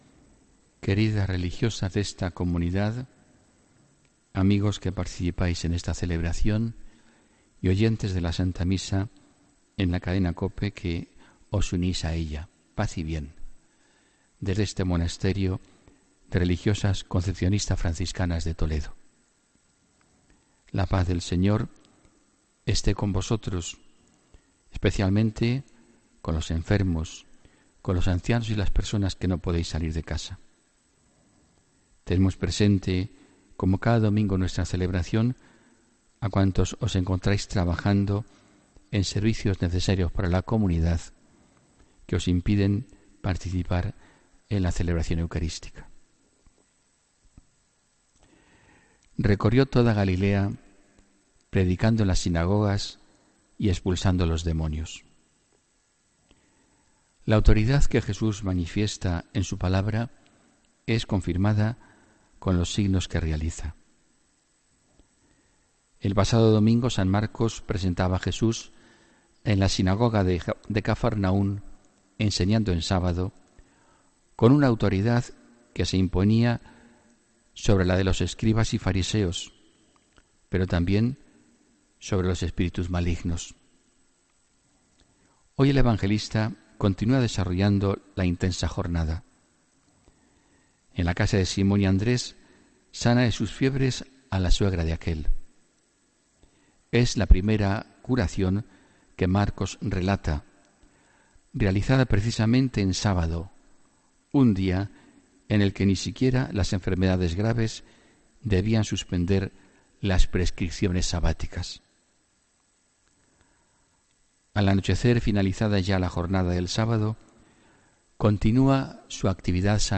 HOMILÍA 4 FEBRERO